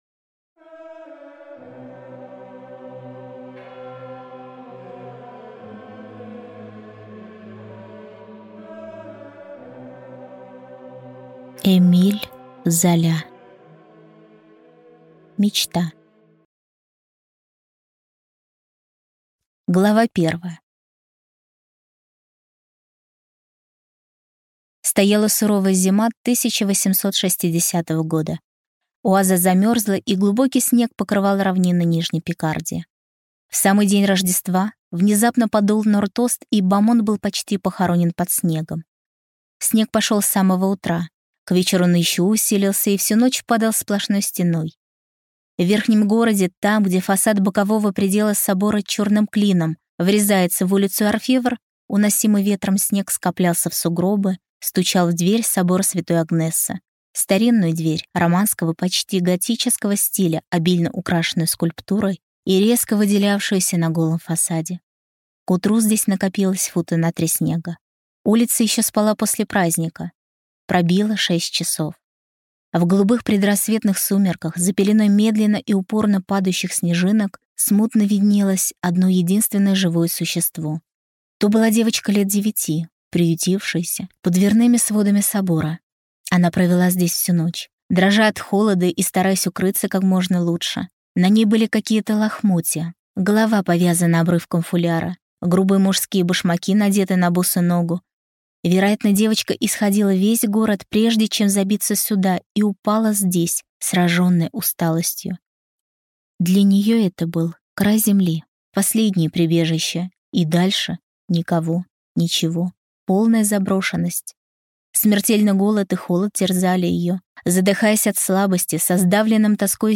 Аудиокнига Мечта | Библиотека аудиокниг